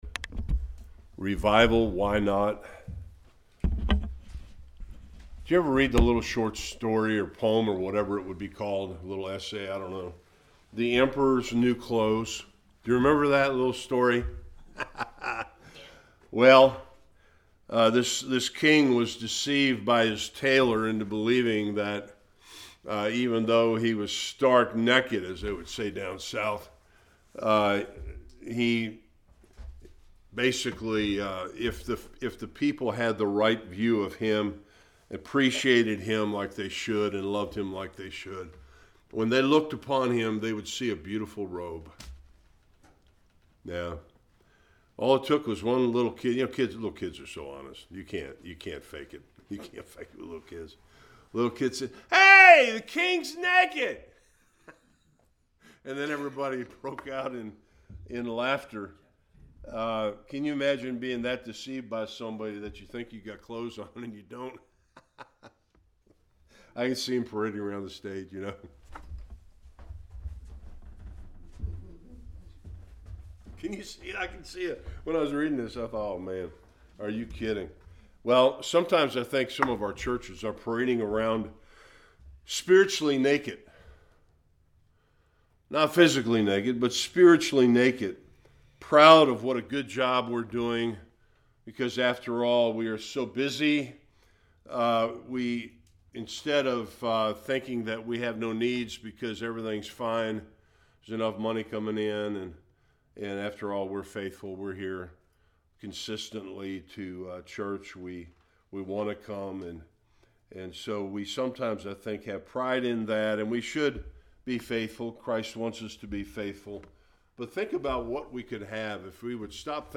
Various Passages Service Type: Bible Study How the Lordship of Christ and discipleship are linked to revival.